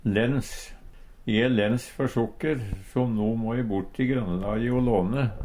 lens - Numedalsmål (en-US)